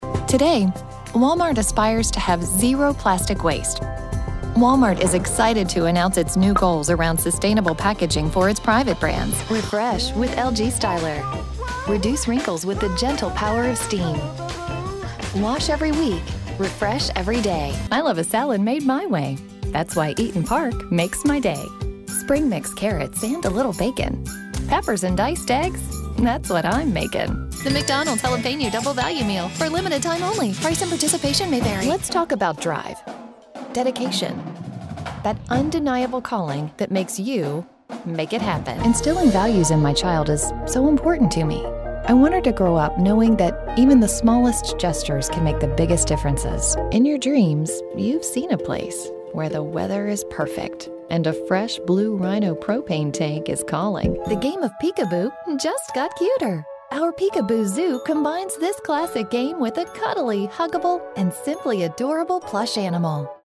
Female Voice Over, Dan Wachs Talent Agency.
Warm, Authoritative, Spokesperson.
Commercial